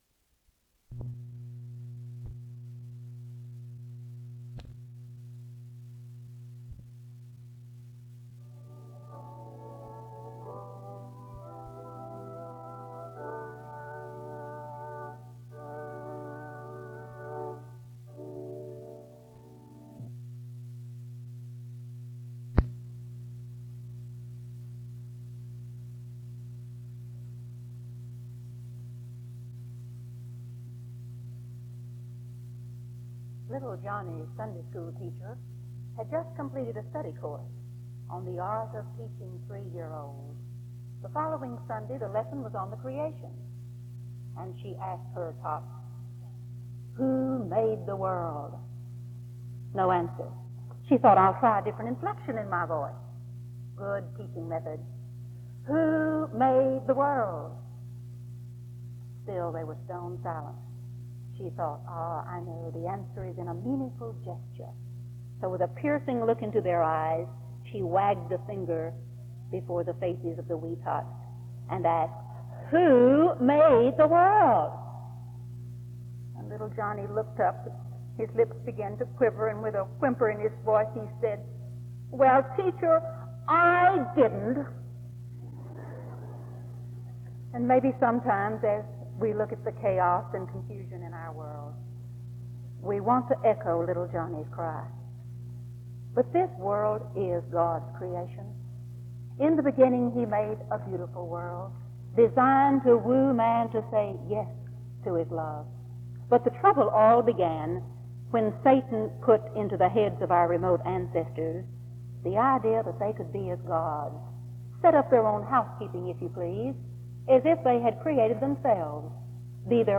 The service closes with music which gets louder toward 46:02.